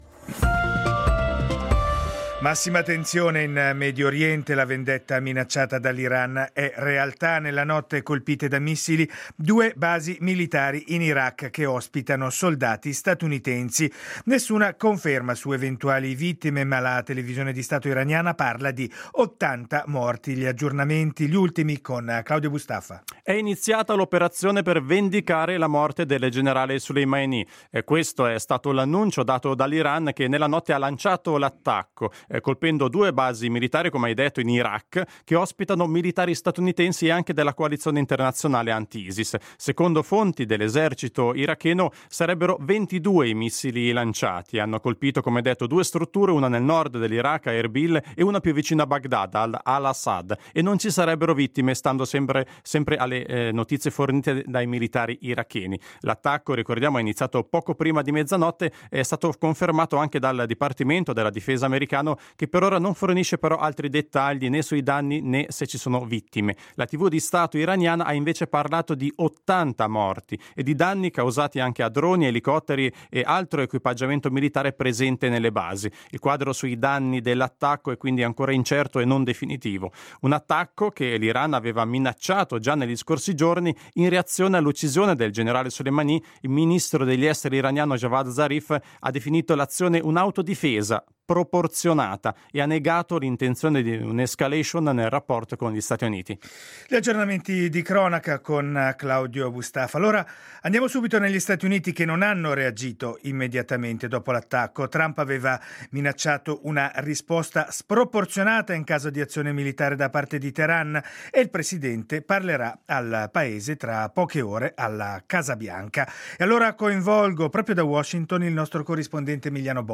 Gli ultimi aggiornamenti e la corrispondenza da Washington nel radiogiornale delle 8